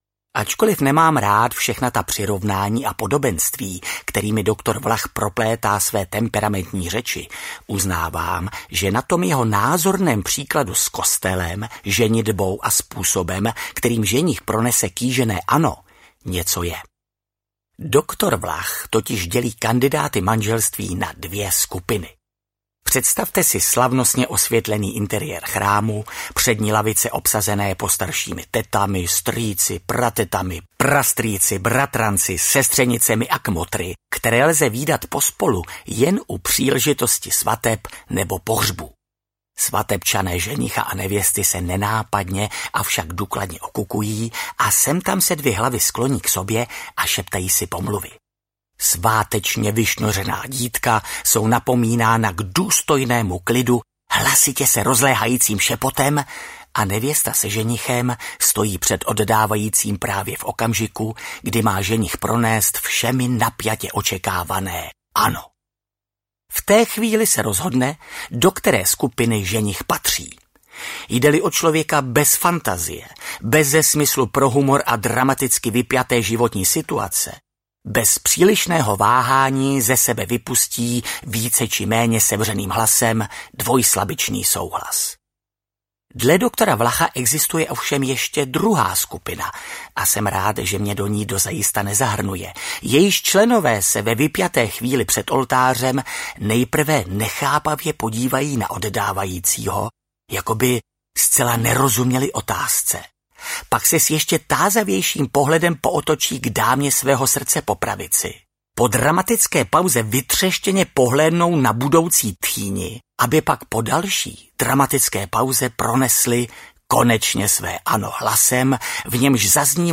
Saturnin se vrací audiokniha
Ukázka z knihy
• InterpretMiroslav Vladyka